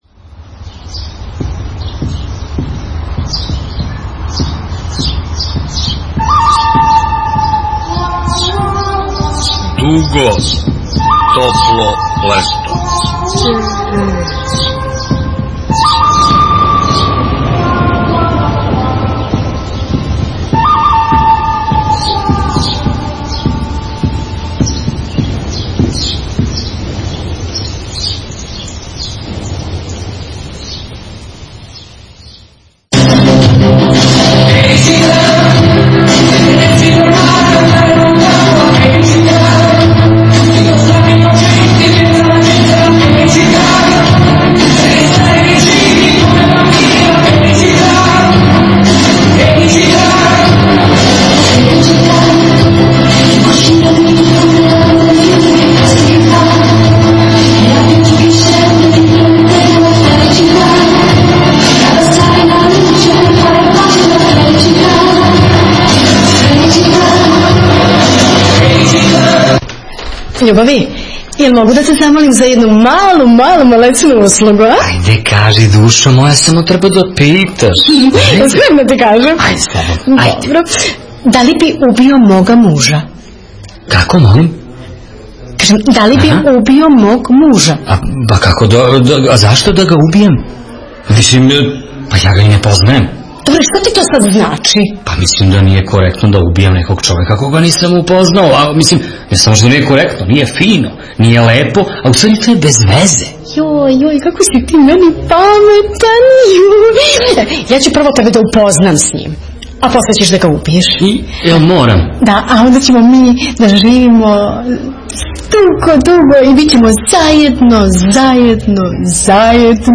У данашњој емисији „Дуго, топло лето”, првој у овој сезони, разговарали смо са глумцима у представи